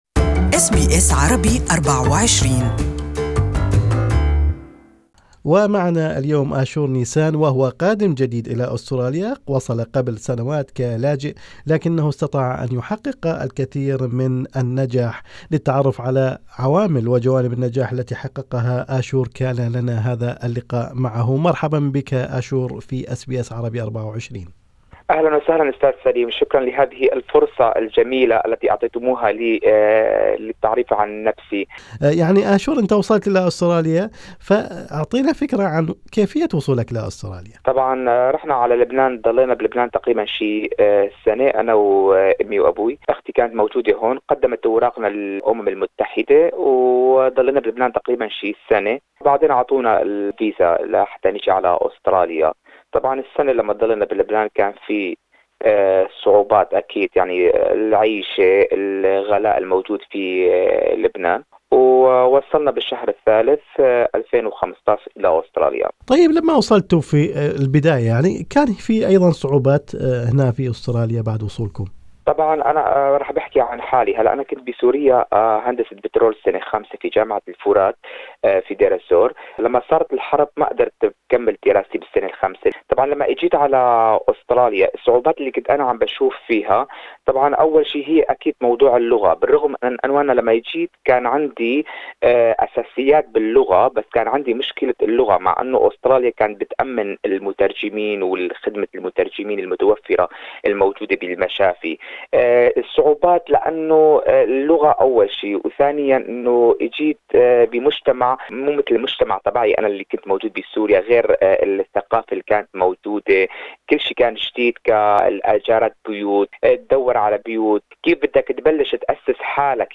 هذه المقابلة عبر اثير SBS Arabic24.